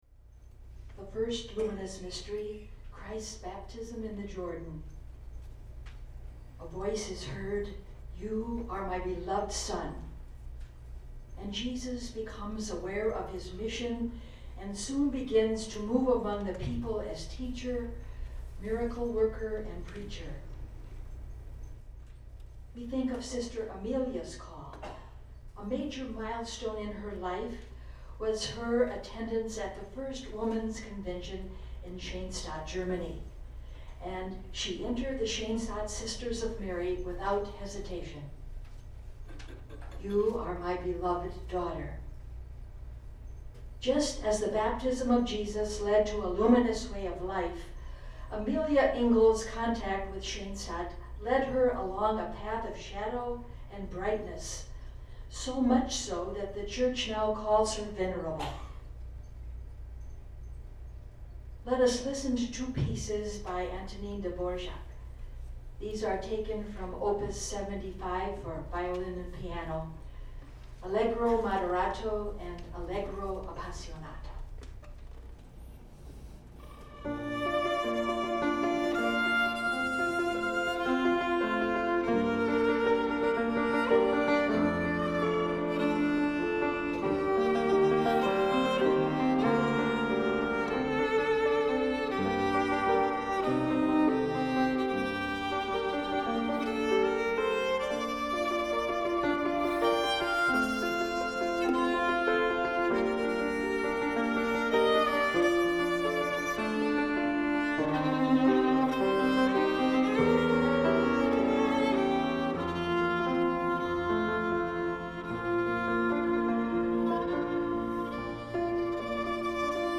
Sinsinawa Mound Center Concert/ Sinsinawa Wisconsin
Violin
Piano
Piano was and old Steinway Baby Grand a bit out of tune.
Room was a low celing conference room
Didn't have an opportunity for a sound check as they rolled the piano in just prior to the concert!
No spot mic on dialog! It was piced up by instrument mics.
AKG C214 on Violin / T.H.E. Mic and AKG C214 on Piano/KB- Mic Pre